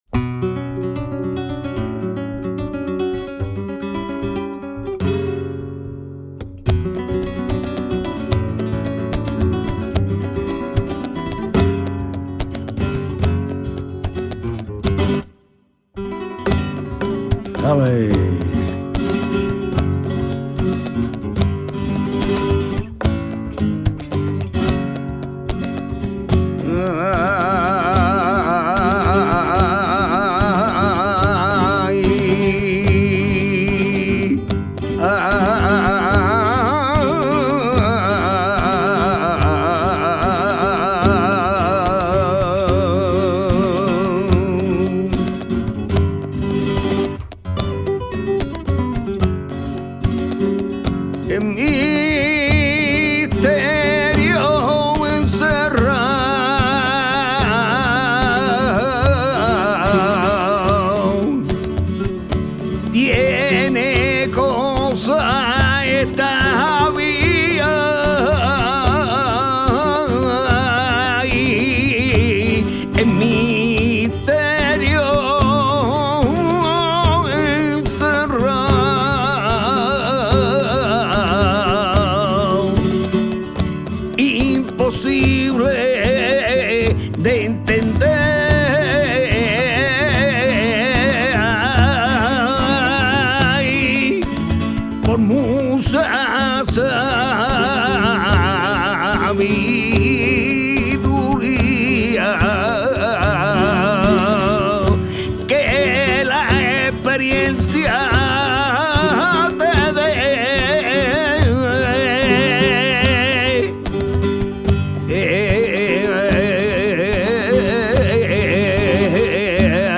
fandango de Lucena et verdial de Córdoba